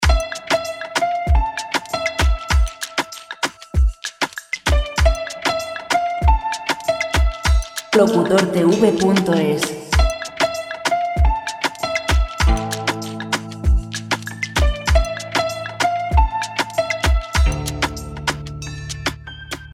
Royalty-free Chillout background music